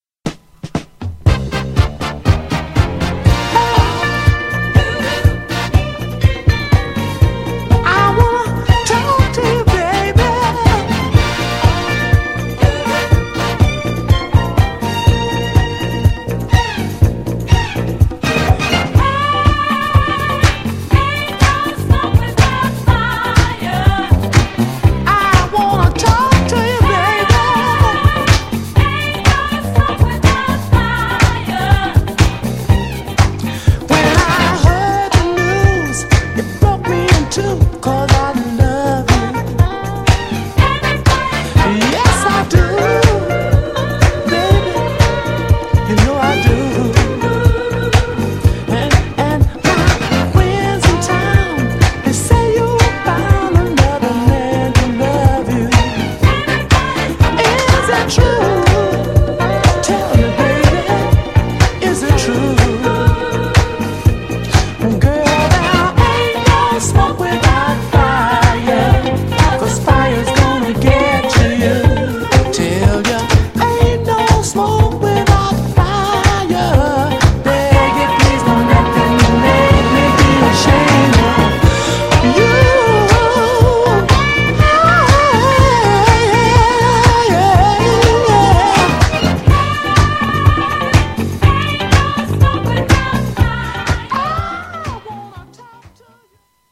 もソウルファンにはたまらない黒い曲!!
GENRE Dance Classic
BPM 96〜100BPM
スモーキー # ソウル